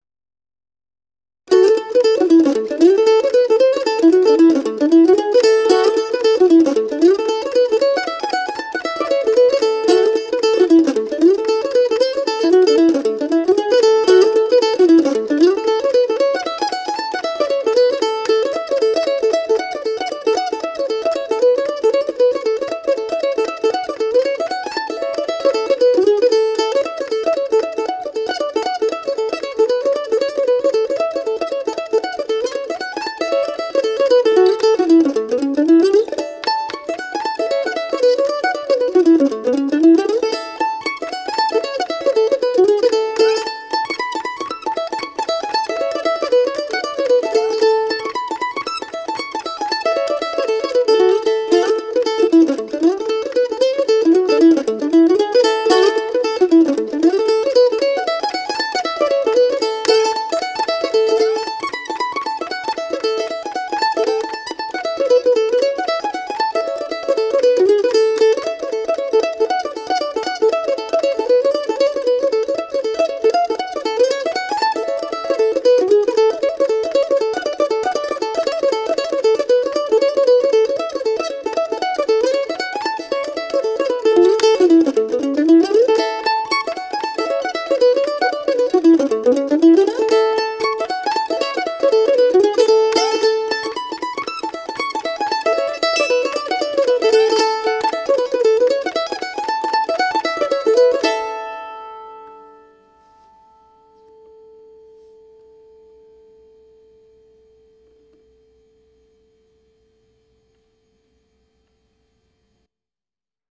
Triggs F-5 Mandolin